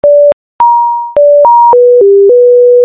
5-6.不思議な音（とっぴんぱらりのぷう）
偶然の産物ですが、作者には「とっぴんぱらりのぷう」と聞こえます。